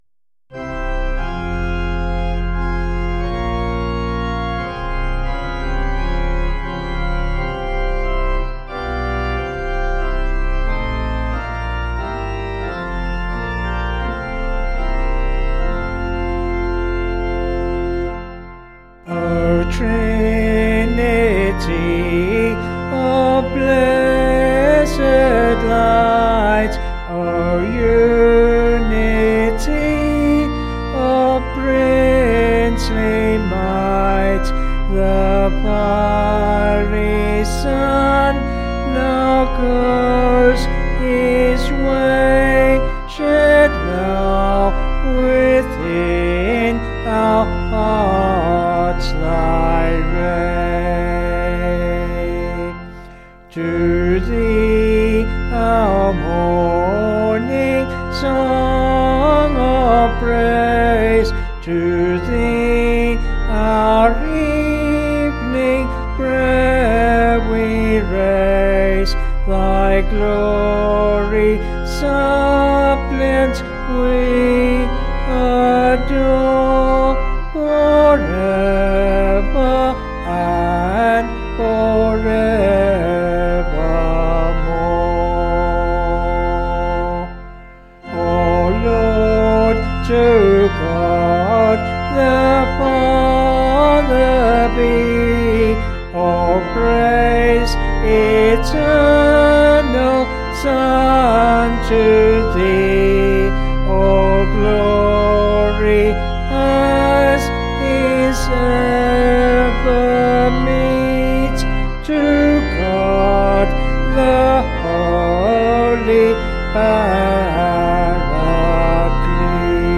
Vocals and Organ   264.2kb Sung Lyrics